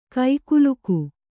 ♪ kaikuluku